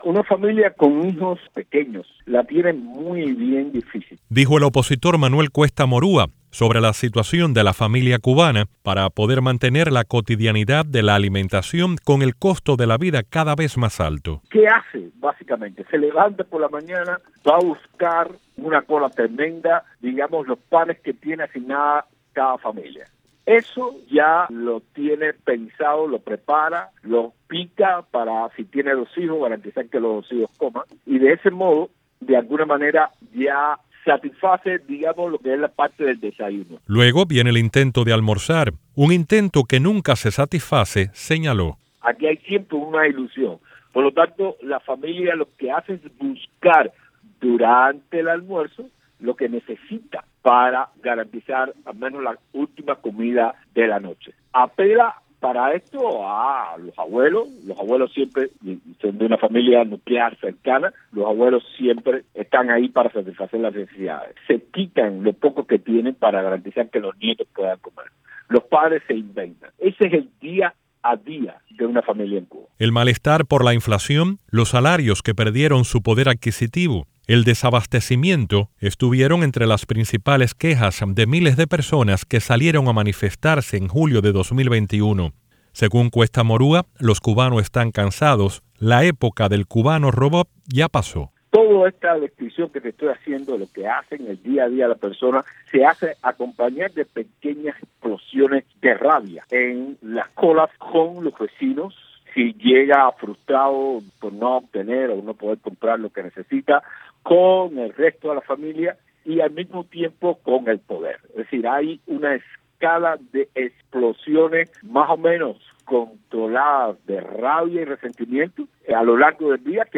Opositor explica Martí Noticias la lucha por la sobrevivencia de los cubanos